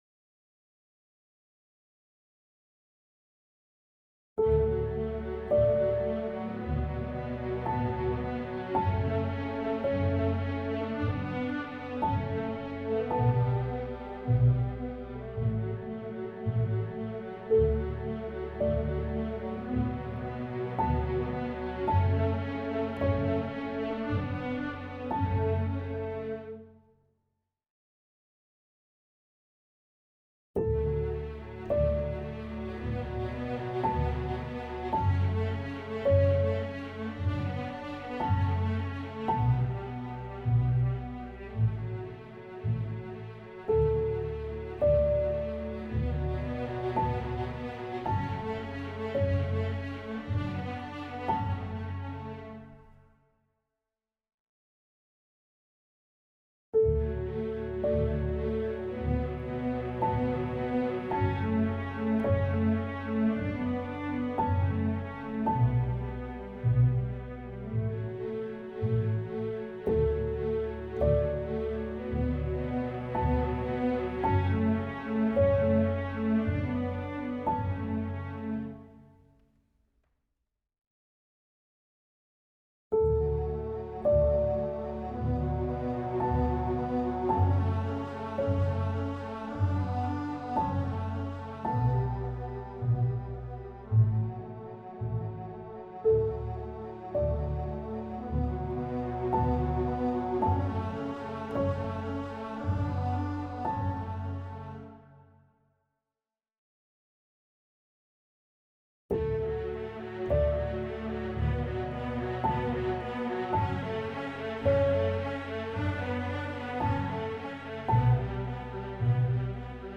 violas